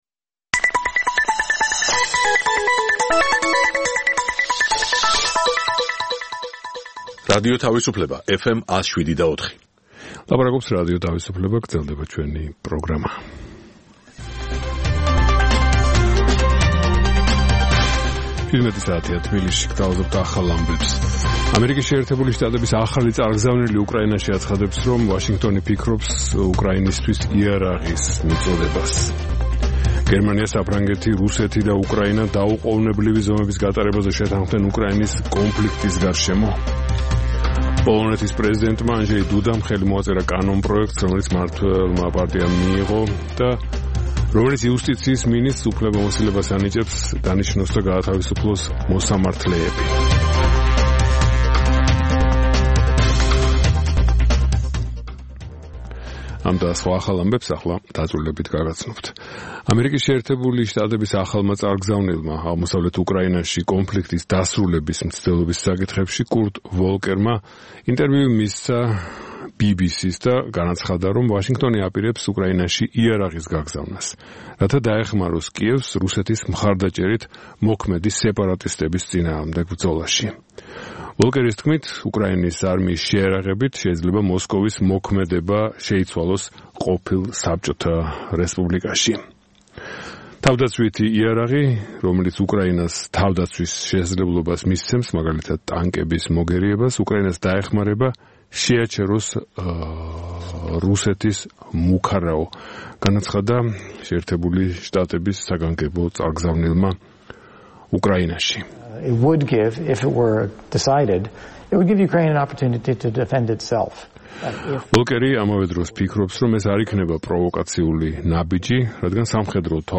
ცნობილ ექსპერტებთან ერთად, გადაცემაში მონაწილეობენ საზოგადოებისთვის ნაკლებად ცნობილი სახეები, ახალგაზრდა სამოქალაქო აქტივისტები. გამოყენებულია "რადიო თავისუფლების" საარქივო მასალები, რომელთაც გადაცემის სტუმრები "დღევანდელი გადასახედიდან" აფასებენ.